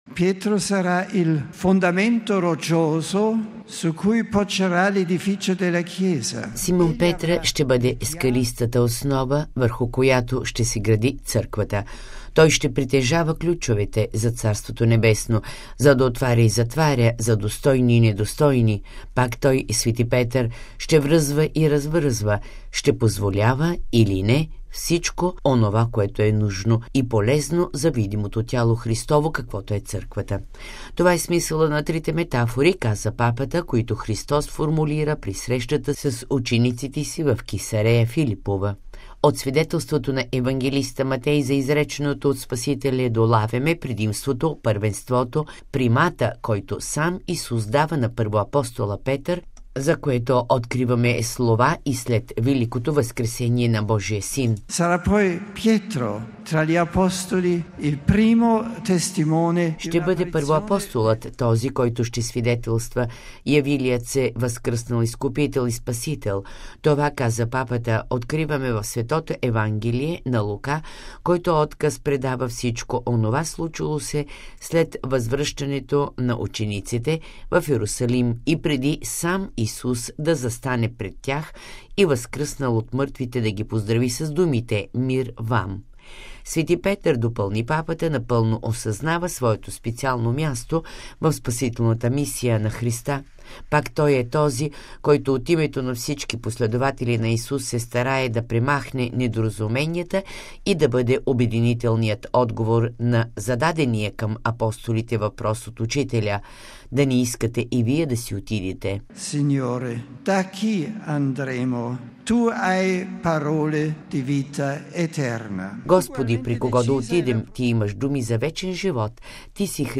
На тази тема, днес Светият Отец посвети обичайната генерална аудиенция, която даде за над 50 хиляди поклонници от Италия и извън пределите й, събрали се на площада пред Ватиканската Базилика.